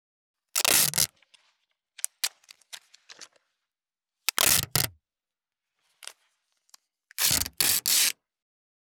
163.ガムテープ【無料効果音】
効果音